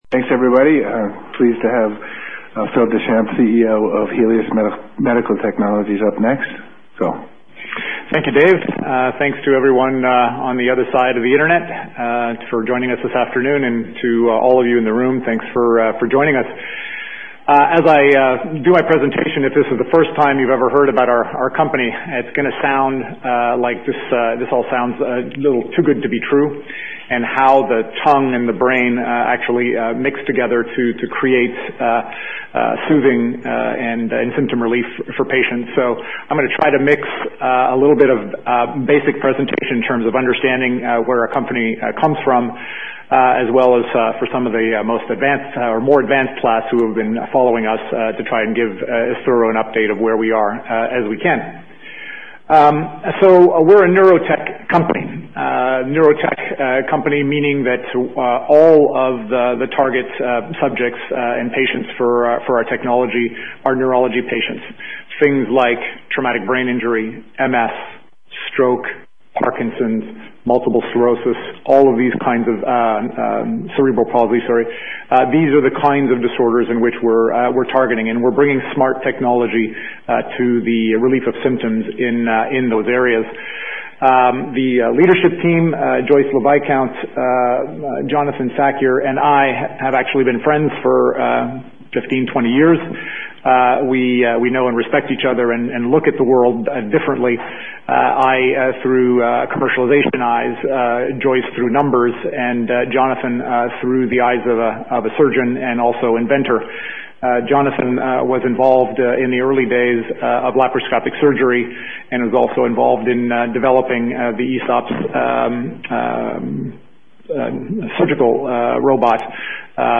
recently spoke at the JMP Life Sciences Investor Conference in New York. He indicated that if/when they receive FDA approval their next targeted indication following balance disorder following TBI will likely be cerebral palsy.